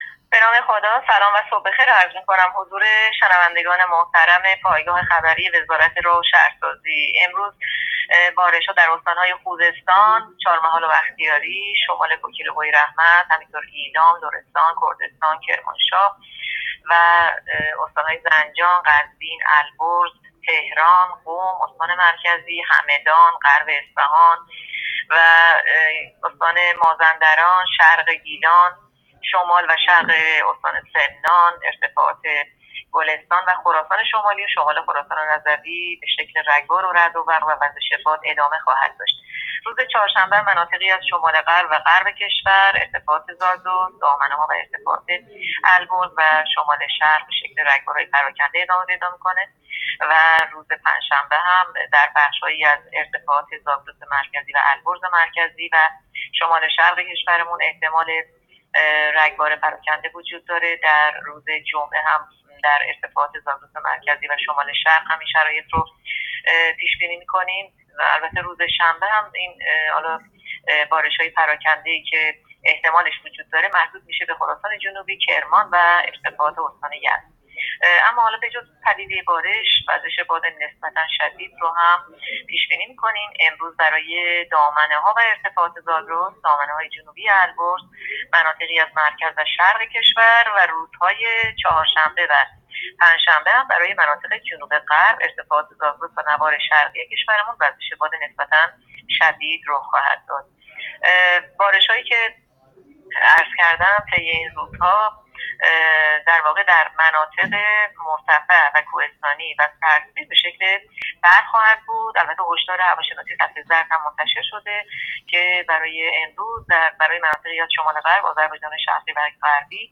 گزارش رادیو اینترنتی پایگاه‌ خبری از آخرین وضعیت آب‌وهوای ۲۹ آبان؛